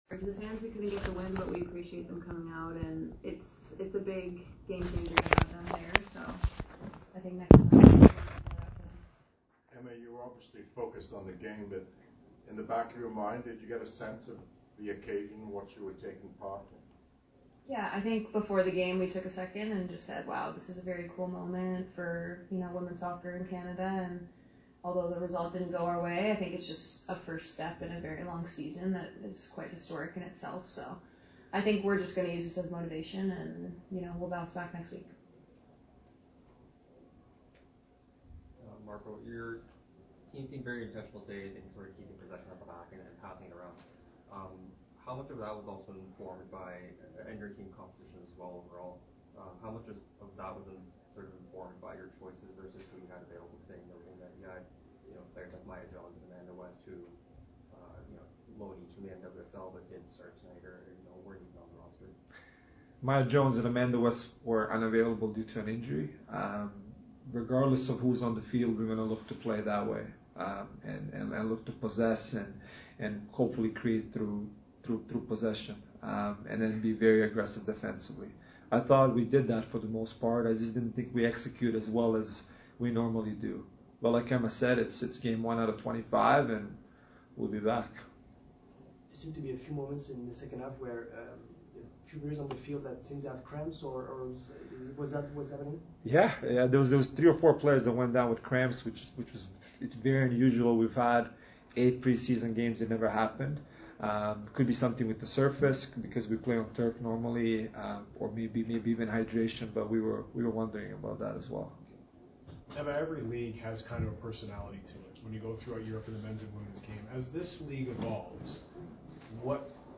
Most of the audio from the post game press conference after the NSL (regular season game) game
post game audio of those two